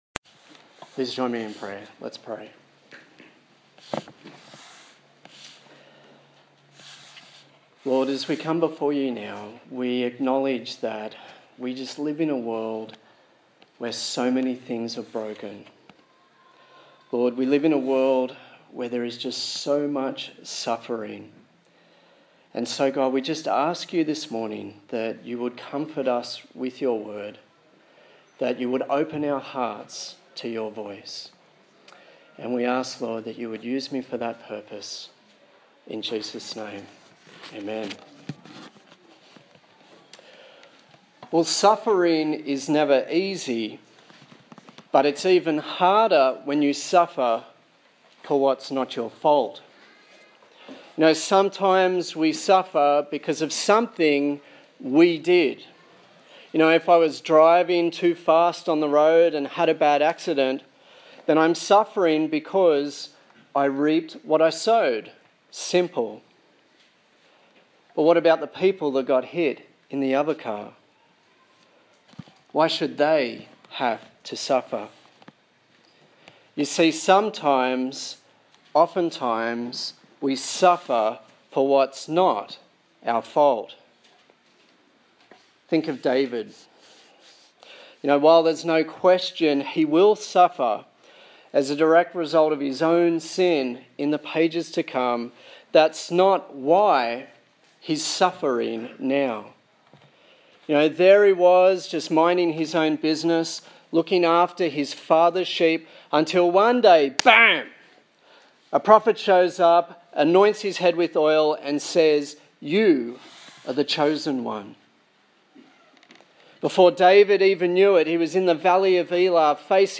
1 Samuel Passage: 1 Samuel 21-22:5 Service Type: Sunday Morning